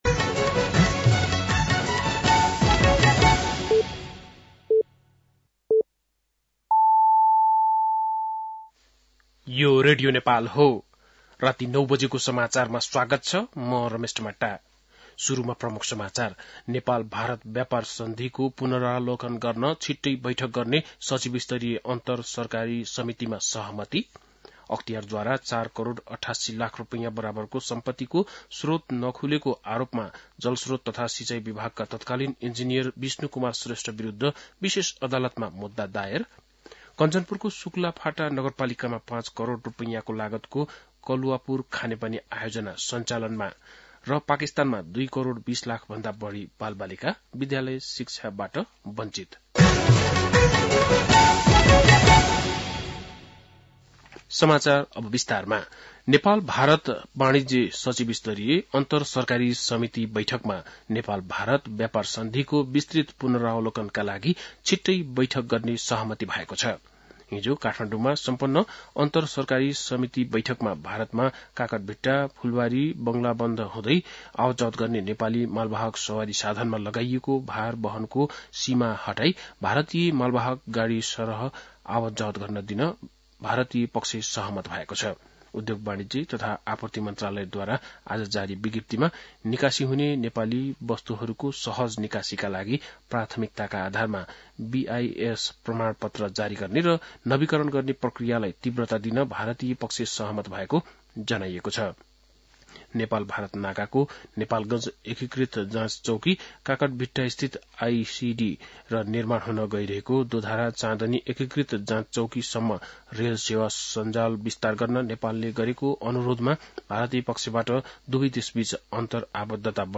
बेलुकी ९ बजेको नेपाली समाचार : २९ पुष , २०८१
9-PM-Nepali-News-9-28.mp3